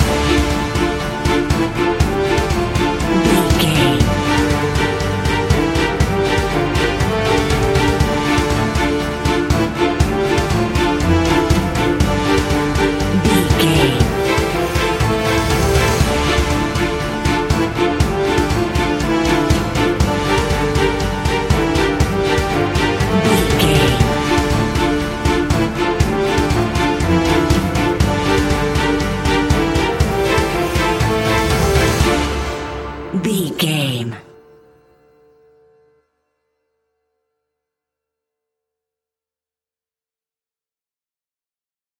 In-crescendo
Thriller
Aeolian/Minor
tension
ominous
eerie
horror music
Horror Pads
horror piano
Horror Synths